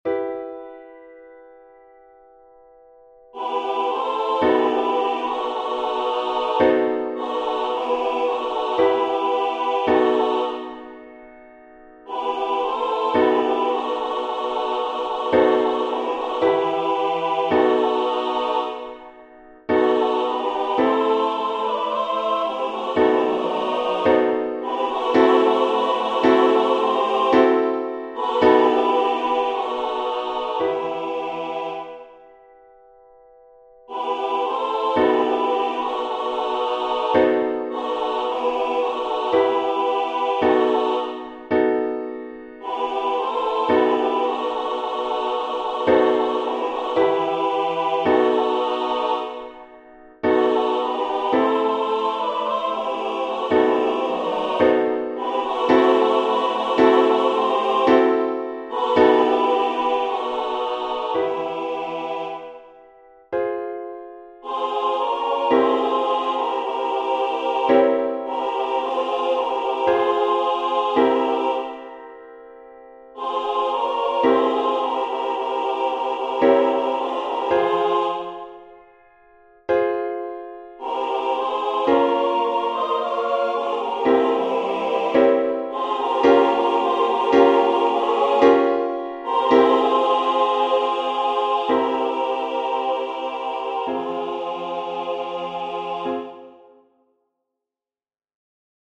tradycyjnej pieśni